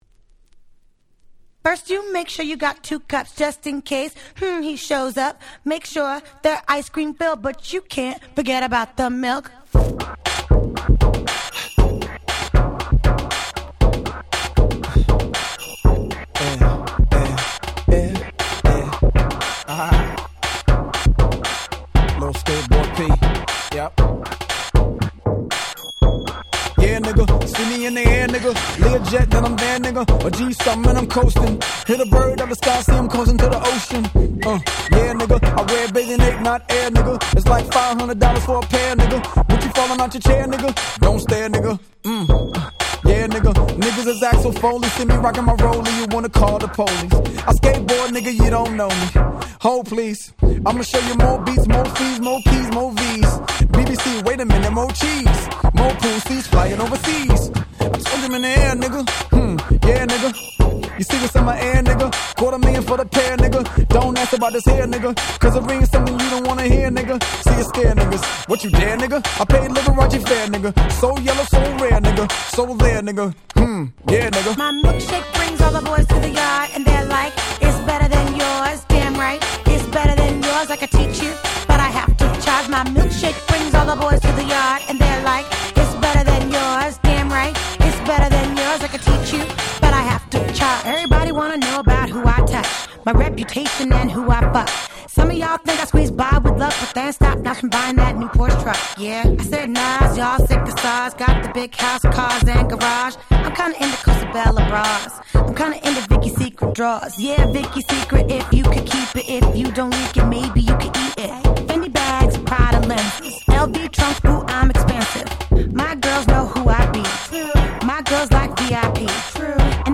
03' Super Hit R&B !!